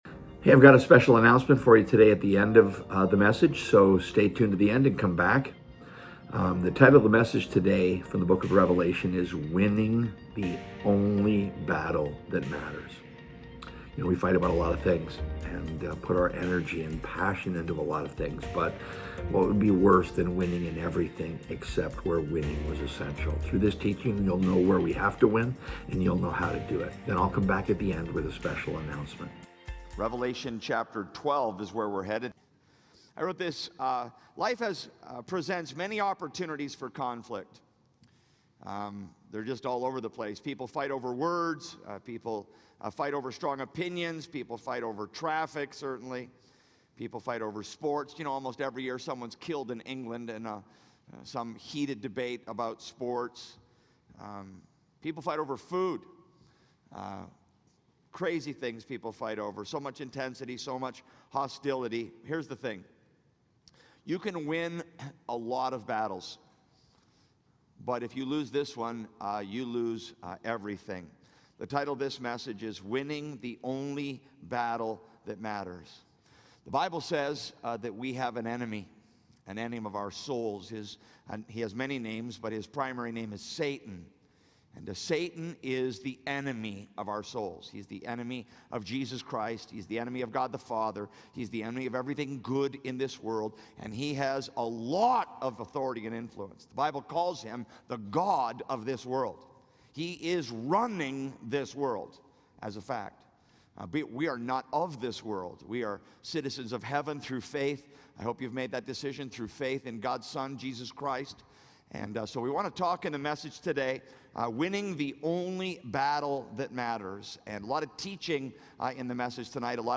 The danger is in winning many of them, yet still losing the only battle that decides everything. This teaching in Revelation 12 will bring clarity to the real conflict (and why ignoring it is not an option).